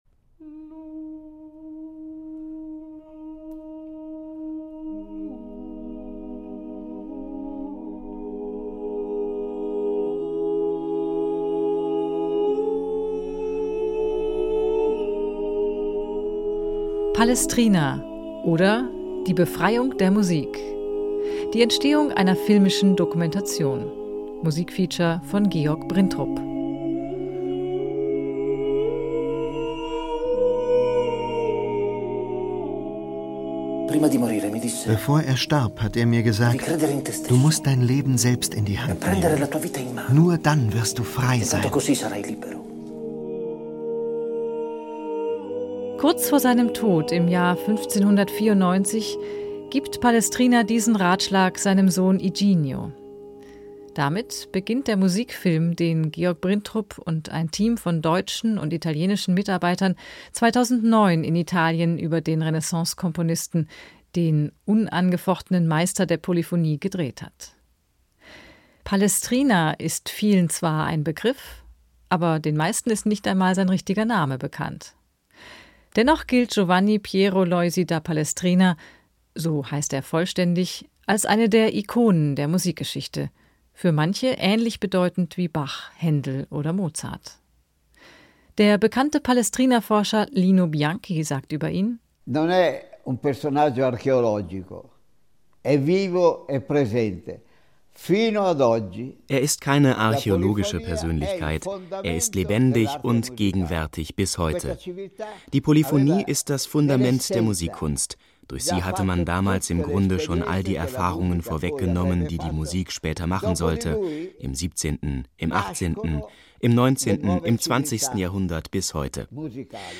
Audio des Hörstücks